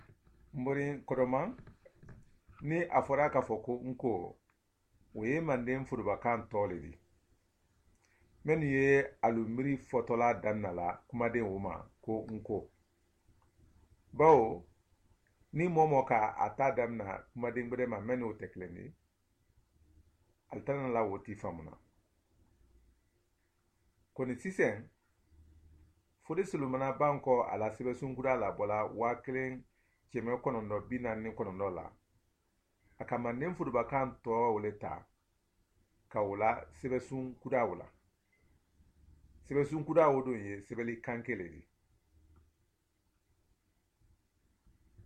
Dialogue: [nko]ߒߞߏ ߝߐߟߊ ߎ߬[/nko]